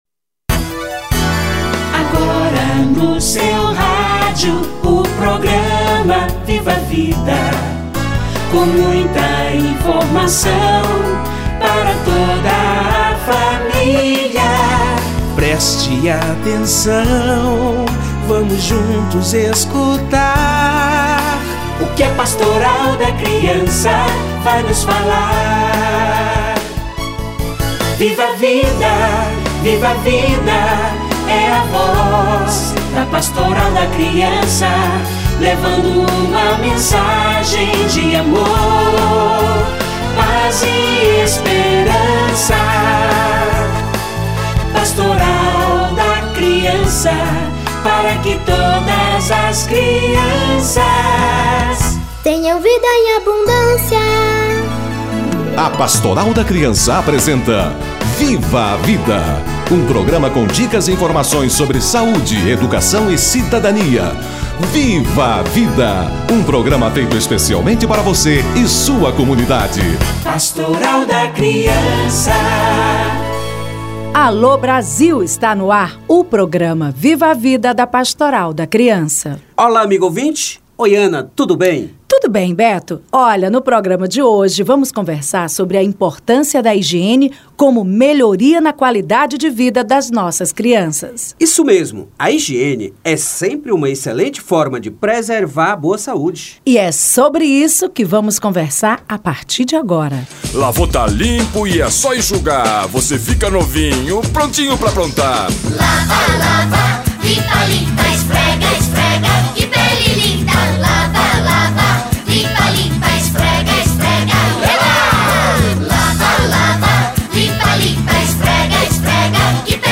A criança e a higiene doméstica - Entrevista